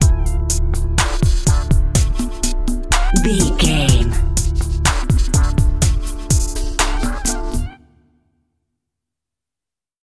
Aeolian/Minor
synthesiser
drum machine